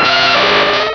Cri de Rhinoféros dans Pokémon Rubis et Saphir.